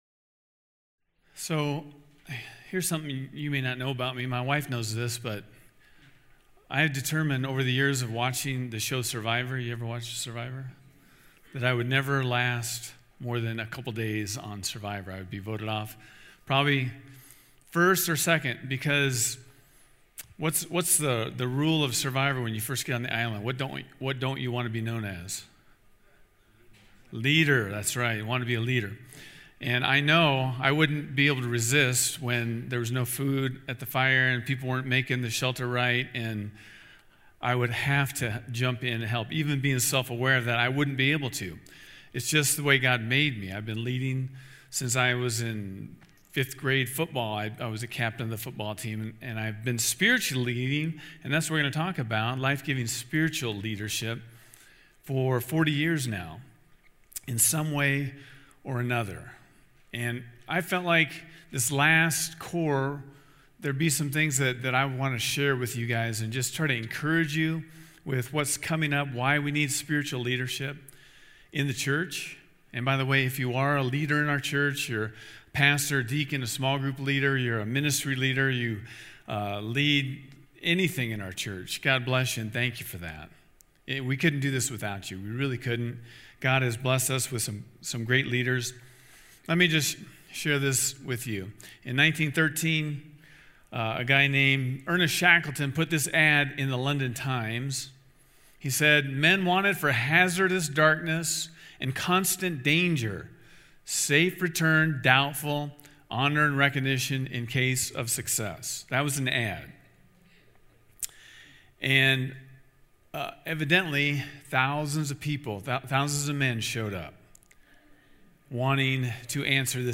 In our semester finale of The Core, we have a very practical and encouraging talk about Life-Giving Leadership. We’ll discuss the purpose and blessing of leadership and some of the exciting, upcoming outreach plans for the summer and fall.